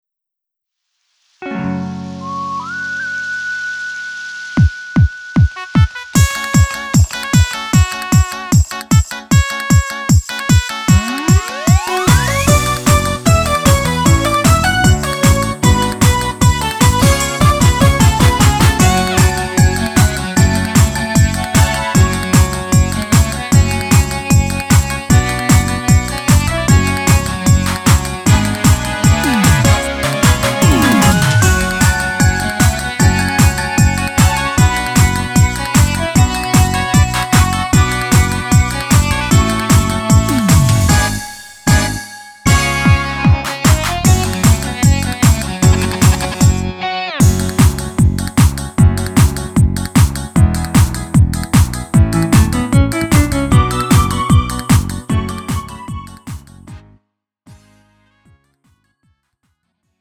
음정 -1키 3:09
장르 가요 구분 Lite MR